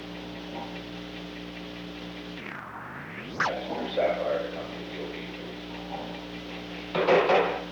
The Old Executive Office Building taping system captured this recording, which is known as Conversation 314-012 of the White House Tapes.